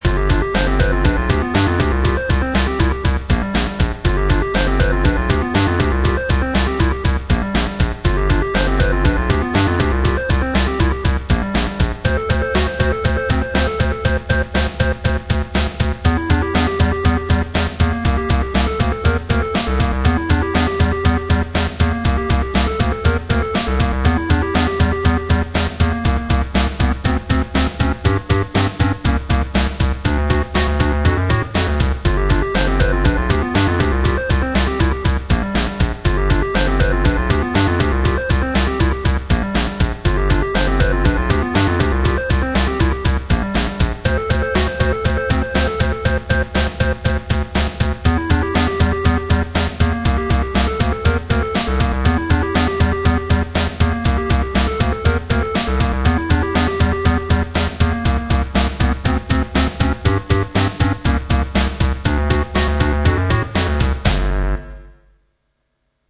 Yes, it's a cover-tune.